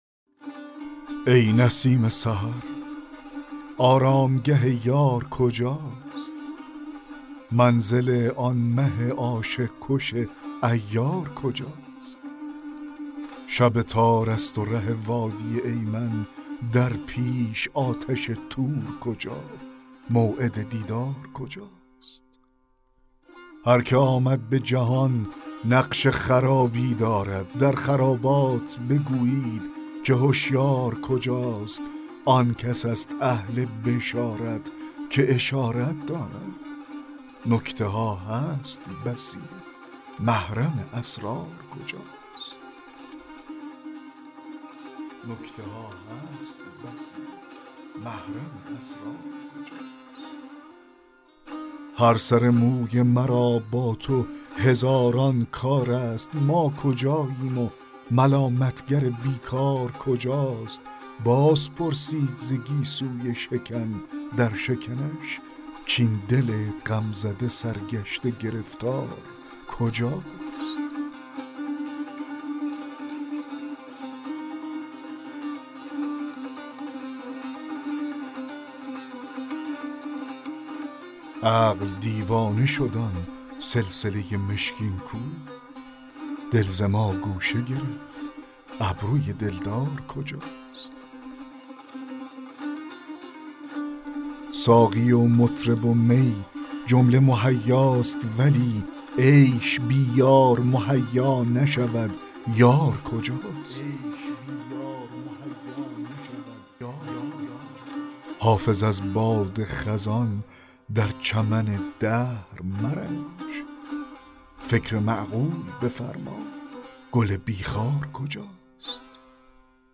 دکلمه غزل 19 حافظ